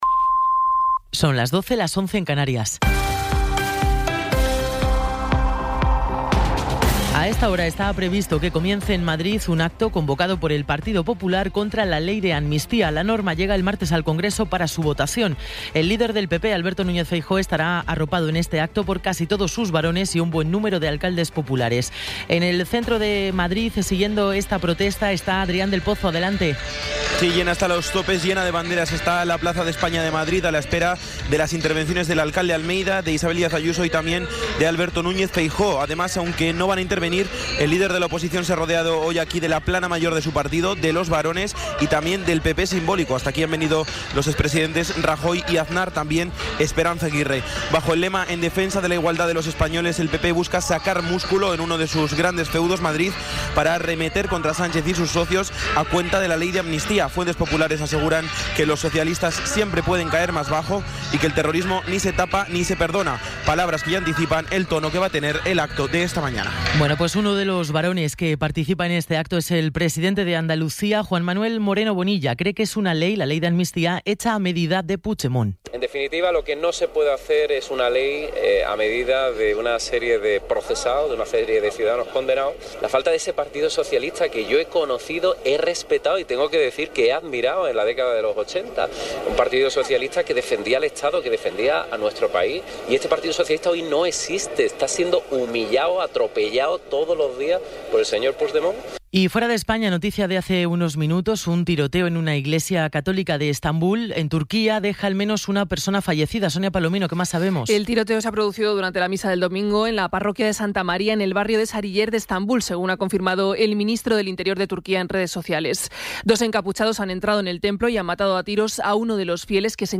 Resumen informativo con las noticias más destacadas del 28 de enero de 2024 a las doce.